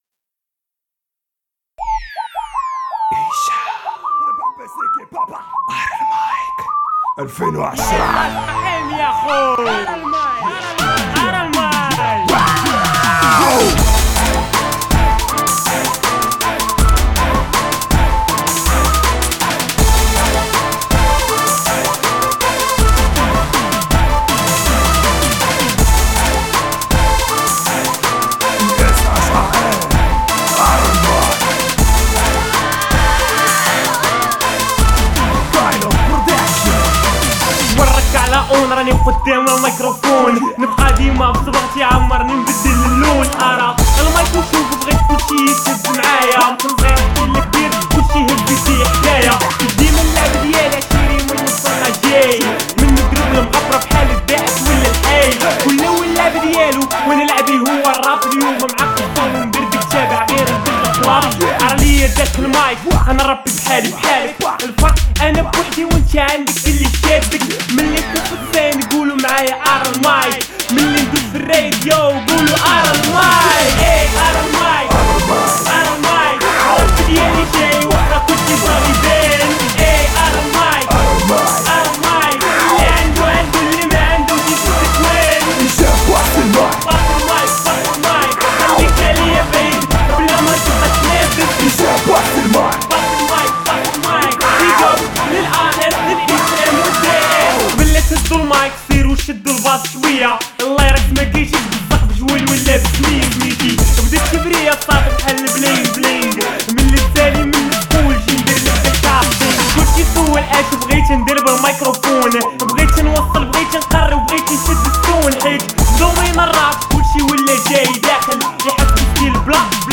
rappeur marocain